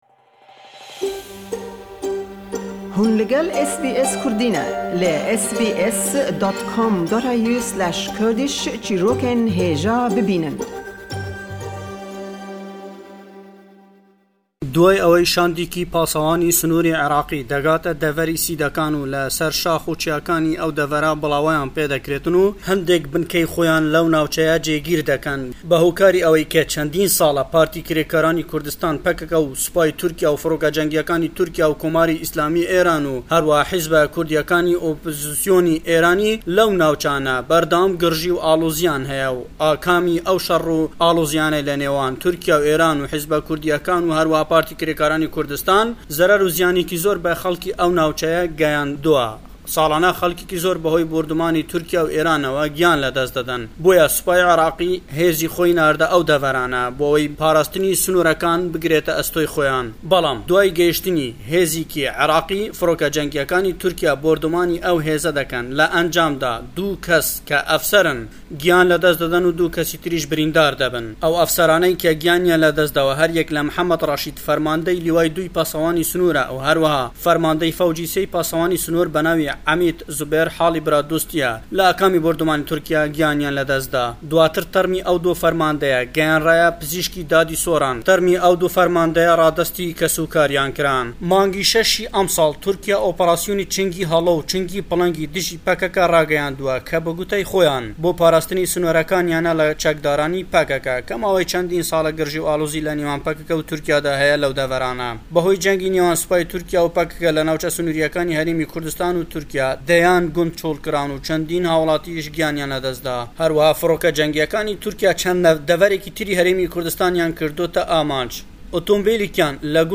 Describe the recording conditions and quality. Le em raporte da le Hewlêre we: Hêrişî Turkîya berdewame le Herêmî Kurdistan, xopîşandan le zorbey şarekanî Kurdistan berêwe deçêt dij be gendellî, û Newey Nwê tawanbardekrên be handanî xellkî bo xopîşandan, û çendî babetî dî le em raporte da ye.